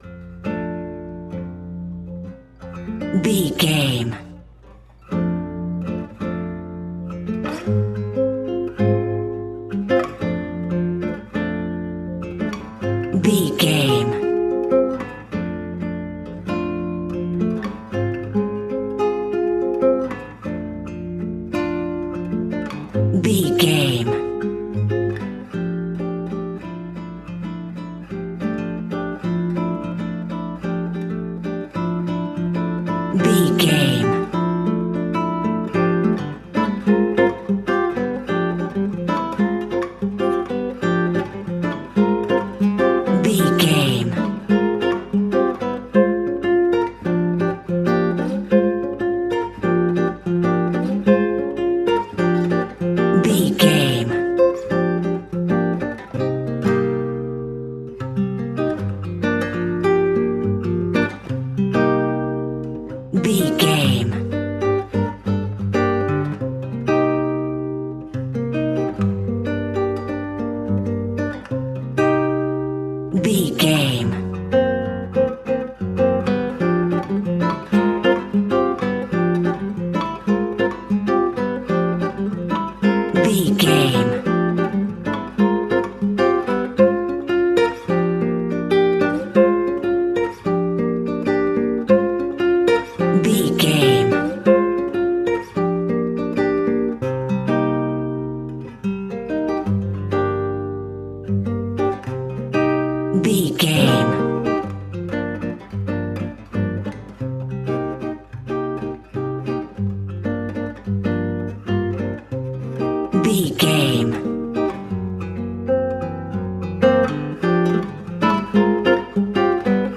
Uplifting
Ionian/Major
maracas
percussion spanish guitar